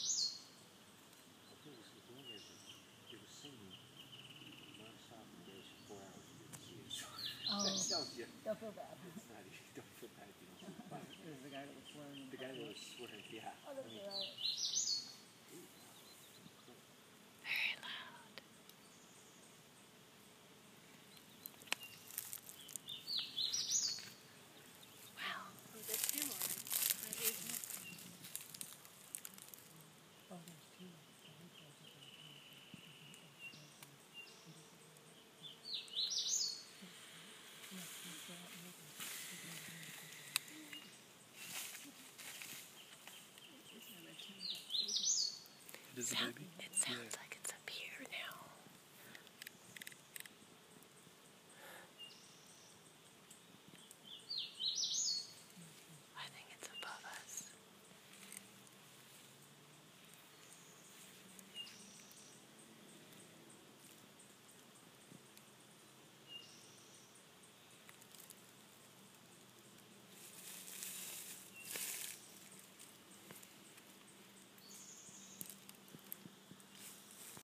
Hooded Warbler
Redwood Regional Park–Skyline Gate area
1 Hooded Warbler — Continuing, heard only
warbler.m4a